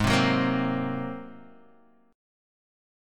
G#9 chord